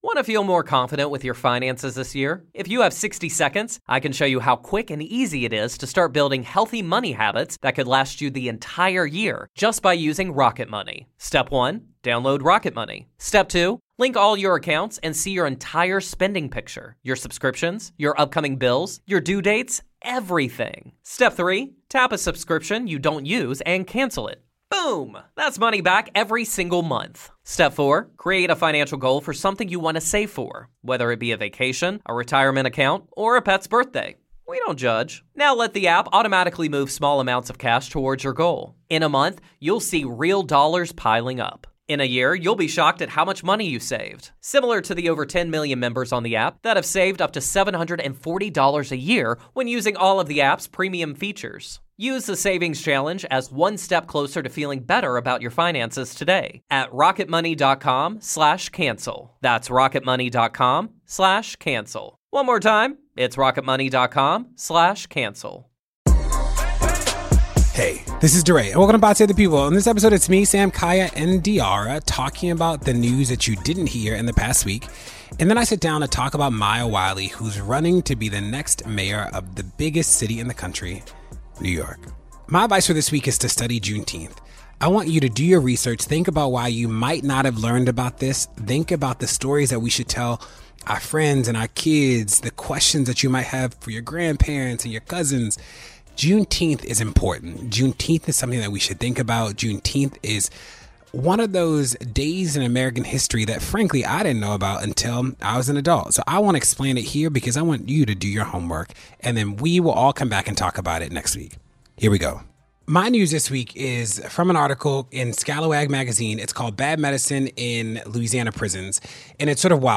DeRay interviews NYC mayoral candidate Maya Wiley ahead of the Democratic primary.